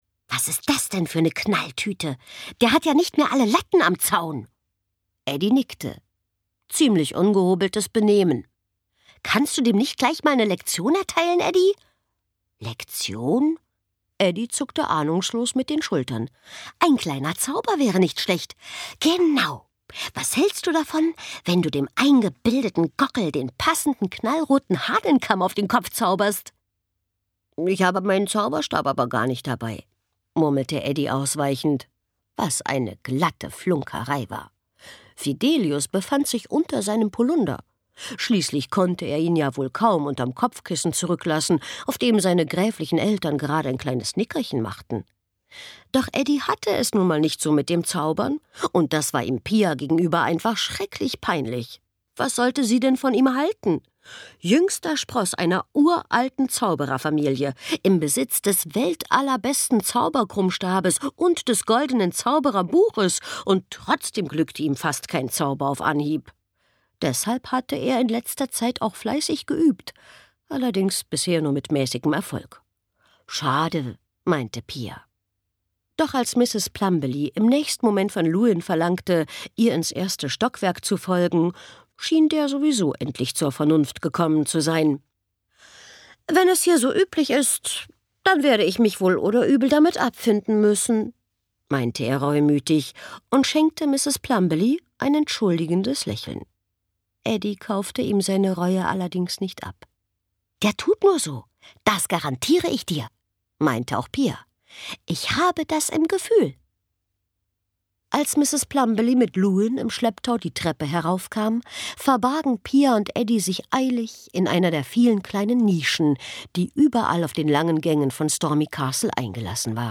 Schlagworte Abenteuer • Burg • Drache • Fledermaus • Freundschaft • Geist • Gespenst • Grusel • Halloween • Hörbuch • Hörbuch ab 7 • Hörbuch für Kinder • Internat • Magie • magische Hörbücher • Schloss • Schule • Schulgeschichte • Schulgeschichten • Streiche • Tiere